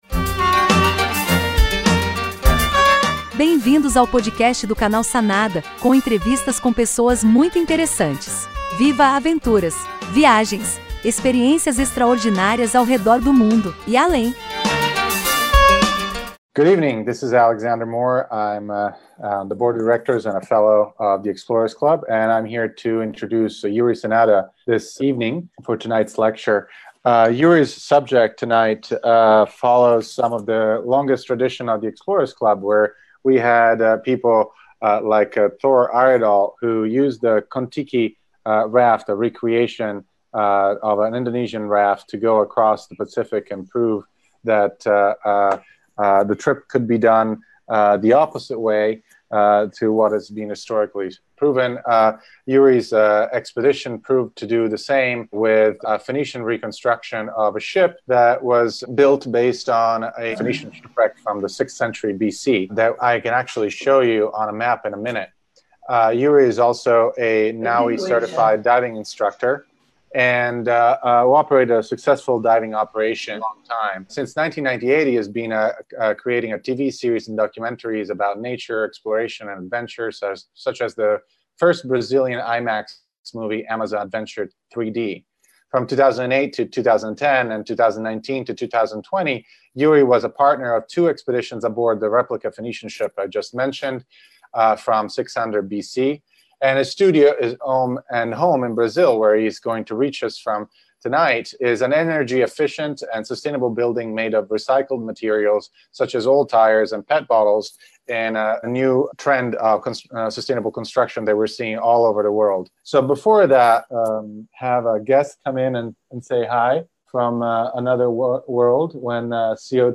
lecture-to-the-ny-explorers-club-about-phoenicians-before-columbus-expeditions.mp3